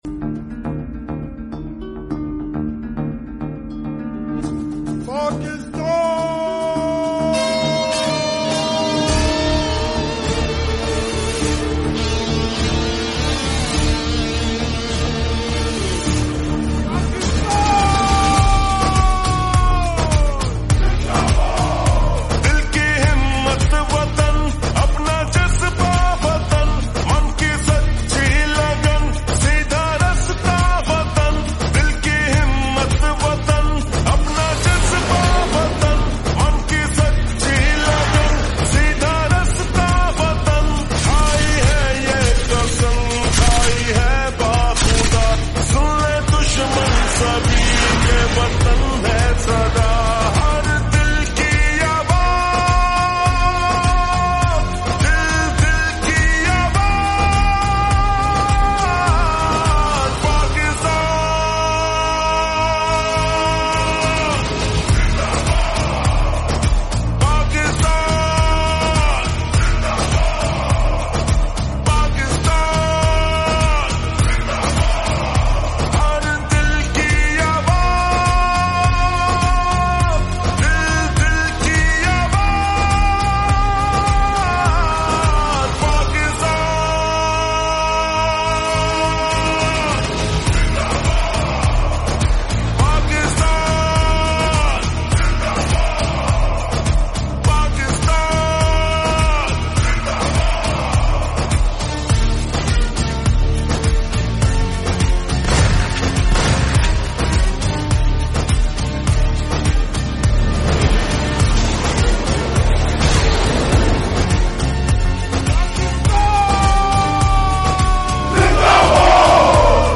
(Slowed+Reverb)